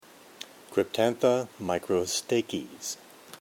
Pronunciation/Pronunciación:
Cryp-tán-tha  mi-cro-stà-chys